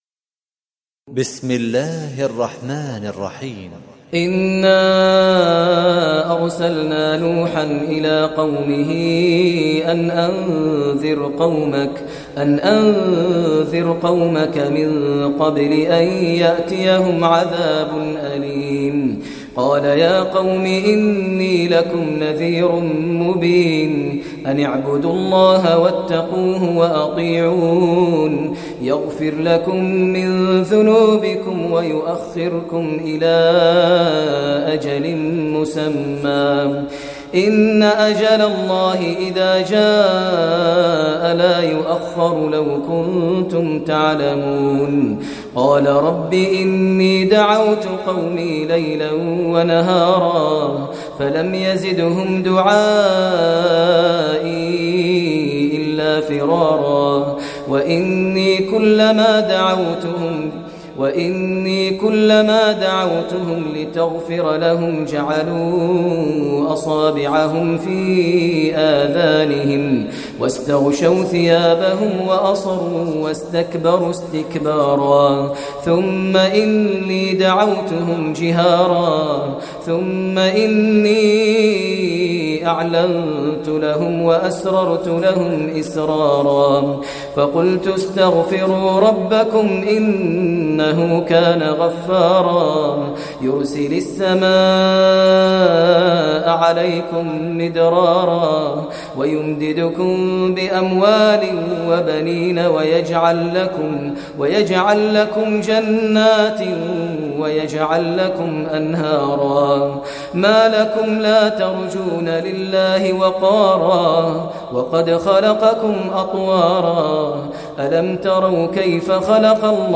Surah Nuh Recitation by Sheikh Maher Mueaqly
Surah Nuh, listen online mp3 tilawat / recitation in Arabic recited by Imam e Kaaba Sheikh Maher al Mueaqly.